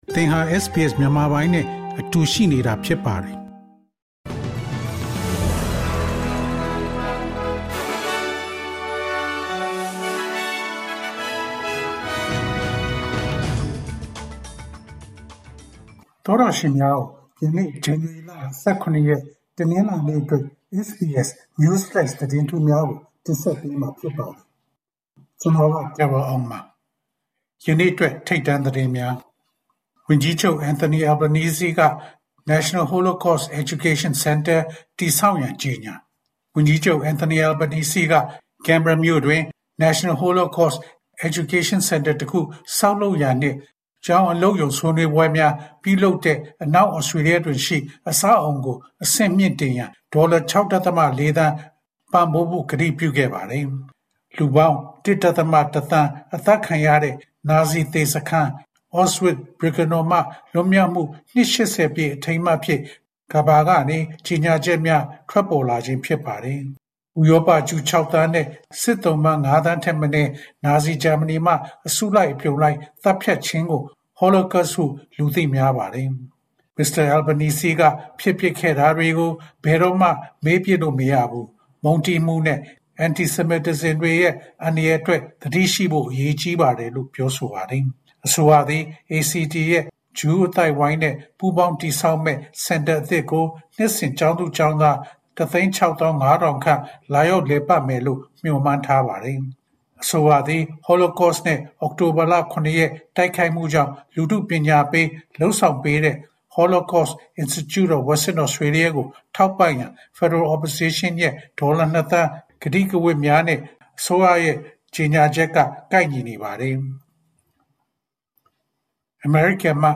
ALC: ၂၀၂၅ ခုနှစ် January ၂၇ ရက်, SBS Burmese News Flash.